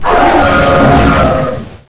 pokeemerald / sound / direct_sound_samples / cries / kyurem_white.aif